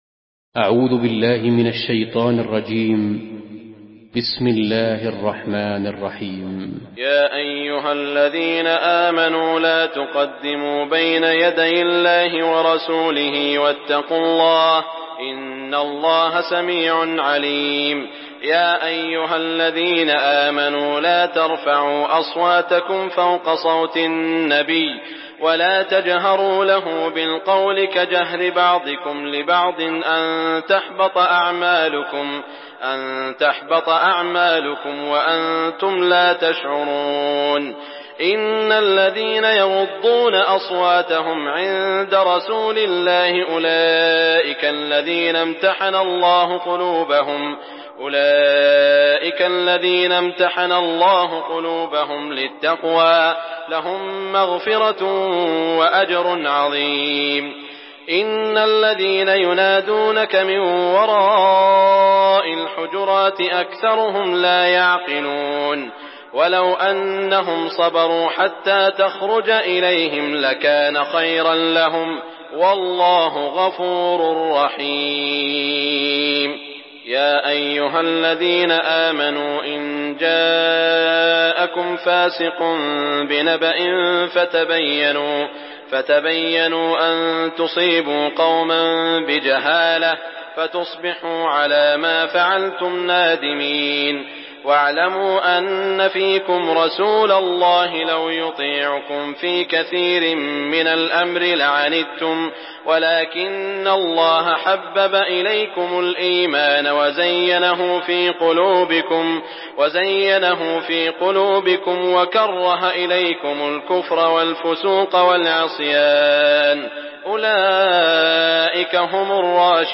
Surah আল-হুজুরাত MP3 by Saud Al Shuraim in Hafs An Asim narration.
Murattal Hafs An Asim